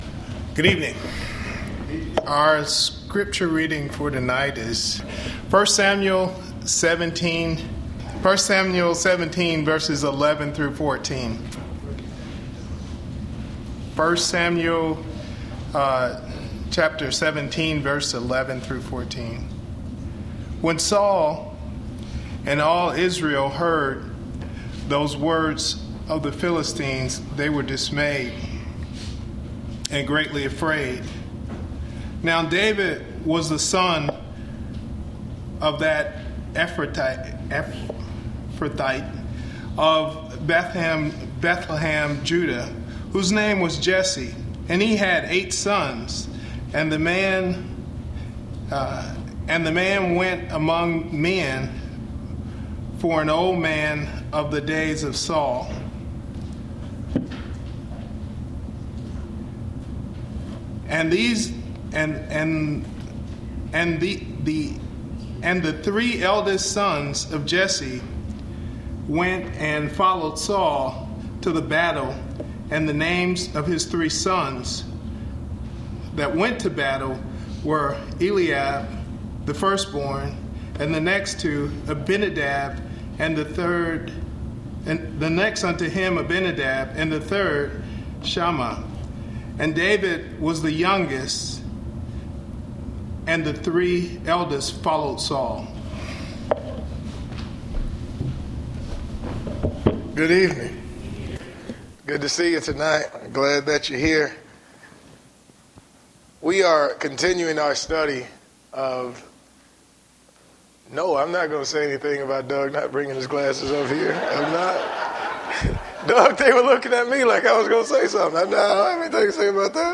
PM Worship